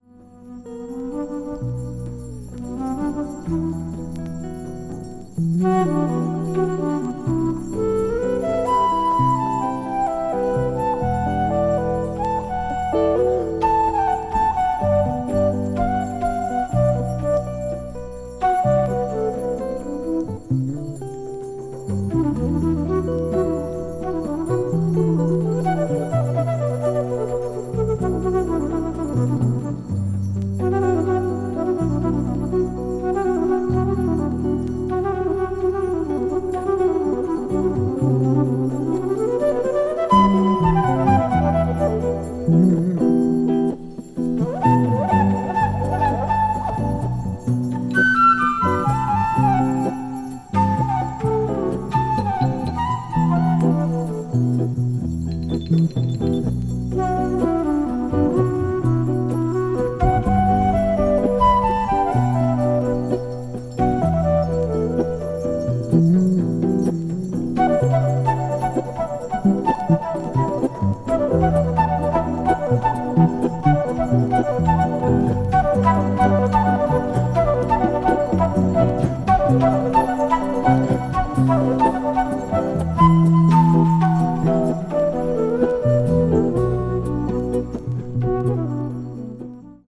一部わずかにチリノイズが入る箇所あり
シドニーのオペラ・ハウスでのライヴ・レコーディング音源。
柔らかなサウンドも気持ち良いです。